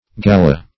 Gala \Ga"la\, n. [F. gala show, pomp, fr. It. gala finery, gala;